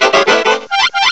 cry_not_charjabug.aif